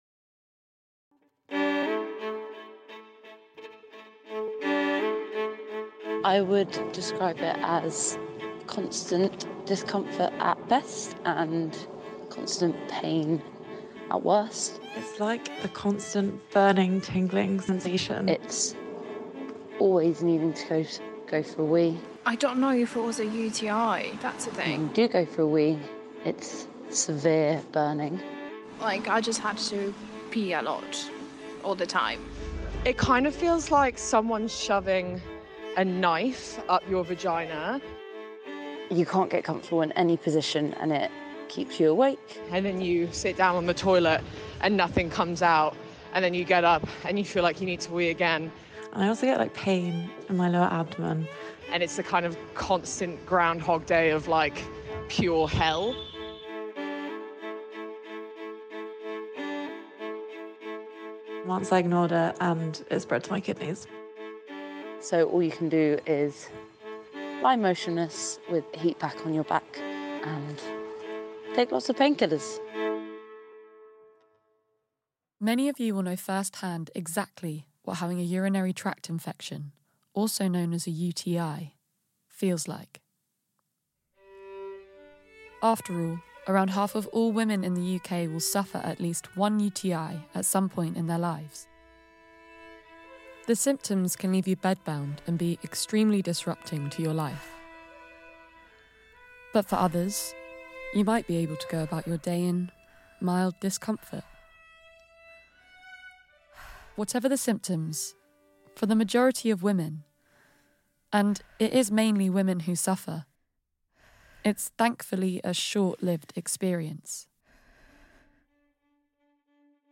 In Episode One, we hear the stories of women at the first stages of CUTI and discover how misconceptions, misogyny and a lack of research, have not only shaped science, but also the patients’ experiences…